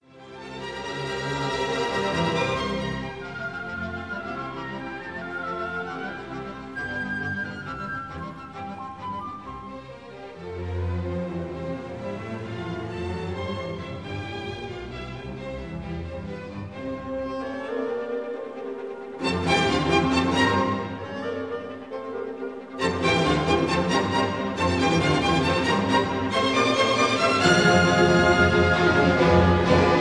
stereo recording